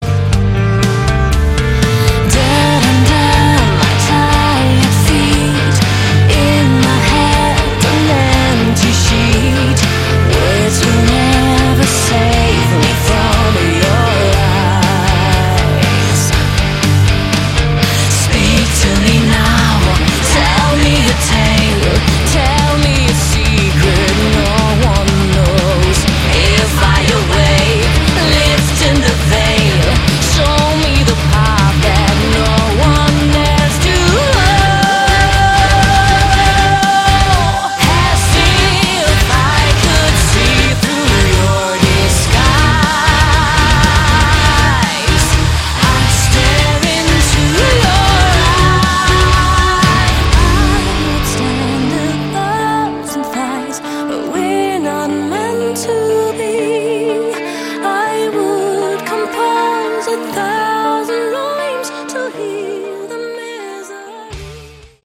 Category: Melodic Prog Metal
vocals
violin
keyboards
guitar
drums
cello, backing vocals
bass